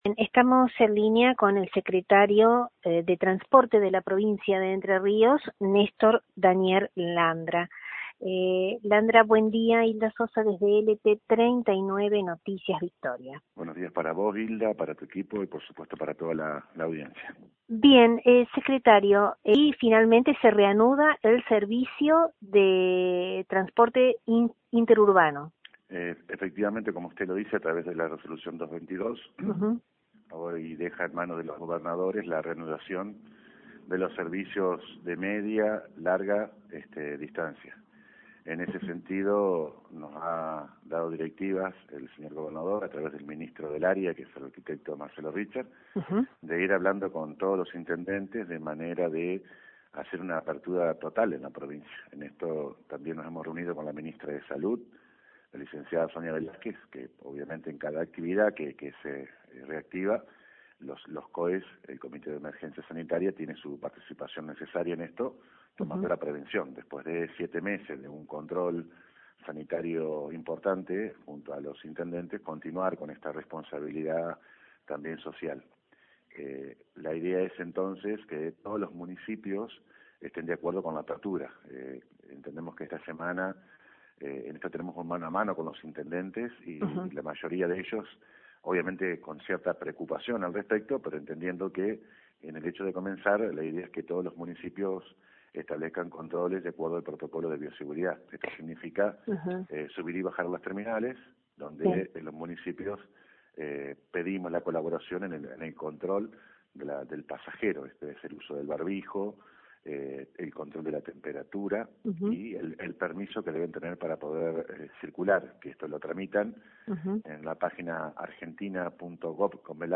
Reapertura de viajes interurbanos micros & trenes: habló con LT39 NOTICIAS el Secretario Provincial de Transporte Néstor Landra
Desde LT39 NOTICIAS, dialogamos al respecto con el Secretario Provincial de Transporte, Néstor Daniel Landra; quien detalló los pormenores de este regreso; dispuesto en distintas etapas; donde en esta primera etapa, las empresas operadoras de servicios para turismo nacional y las permisionarias de servicios de transporte automotor de carácter público, de tráfico libre y ejecutivo, podrán prestar servicios de traslado a trabajadores esenciales de actividades exceptuadas del aislamiento social, preventivo y obligatorio.